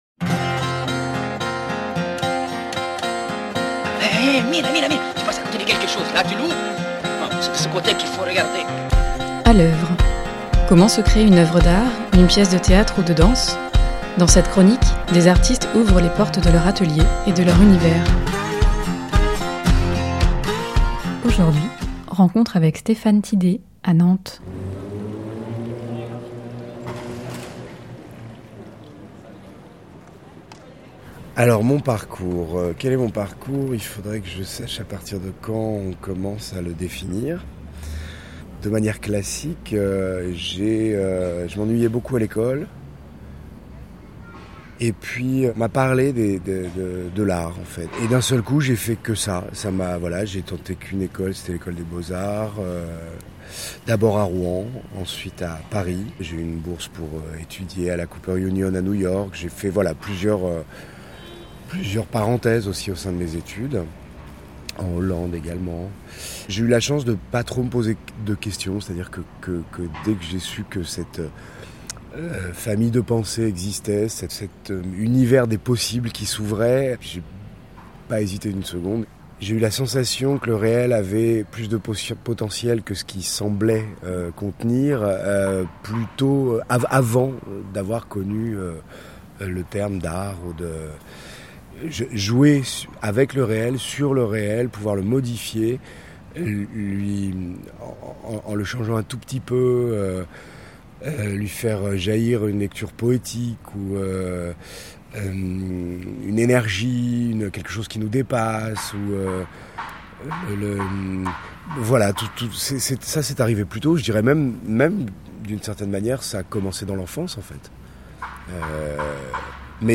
Sons additionnels : Ambiances de ville, Nantes ; place Graslin et allumage de Rideau ; marathon de Nantes 2019 ; forêt de nuit ; vagues s’engouffrant dans les rochers de Punakaiki, Nouvelle-Zélande (enregistrements personnels)